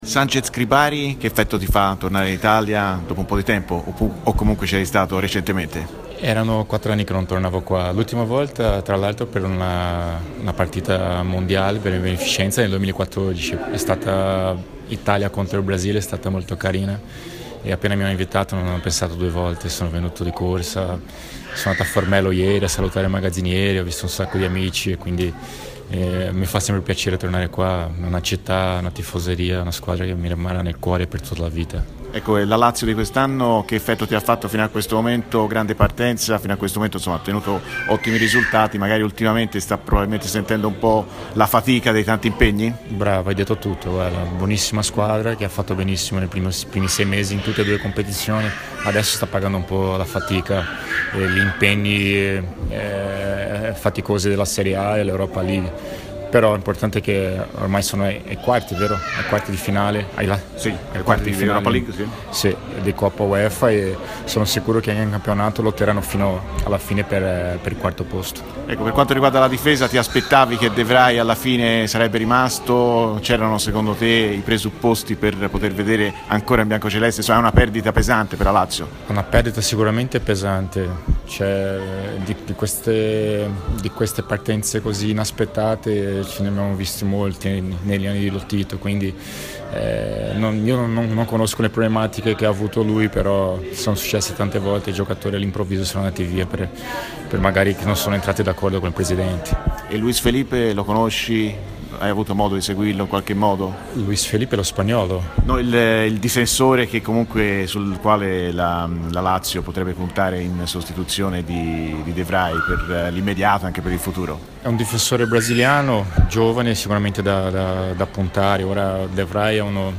Sanchez Cribari, a margine della Partita Mundial, al microfono di RMC Sport © registrazione di TMW Radio